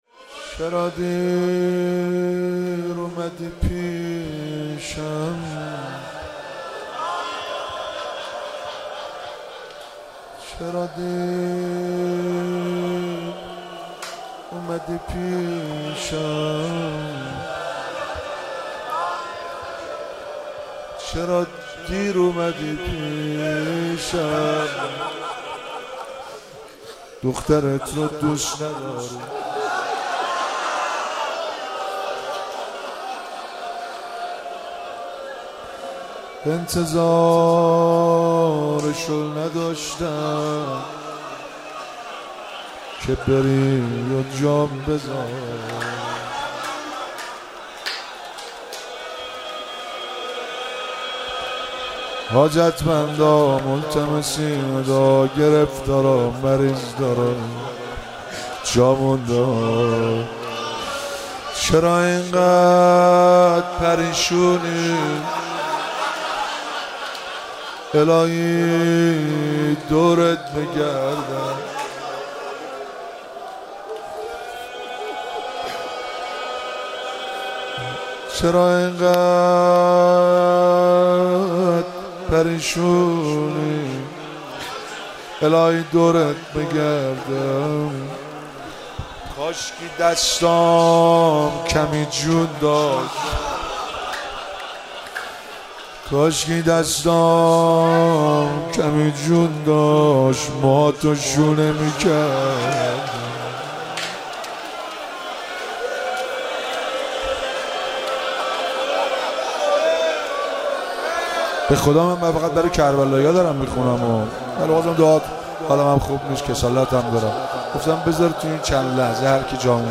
مراسم شهادت امام سجاد(ع)- شهریور 1401